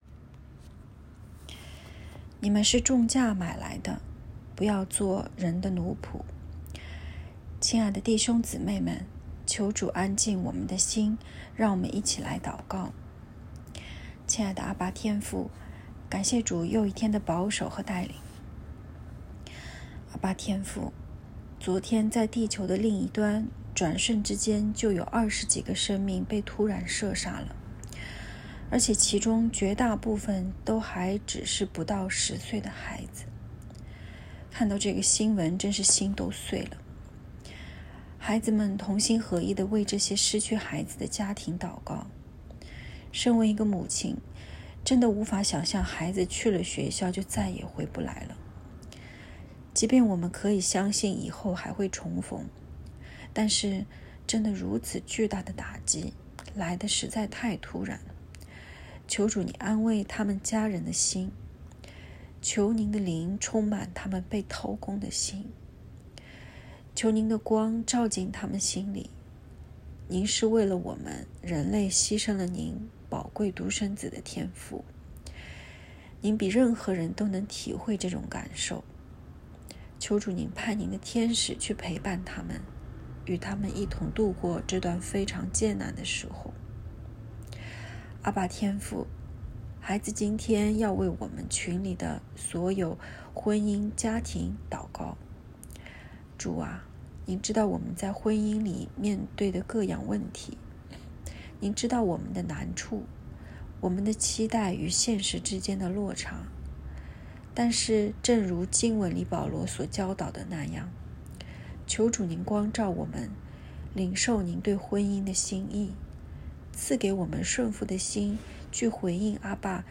✨晚祷时间✨5月26日（周四）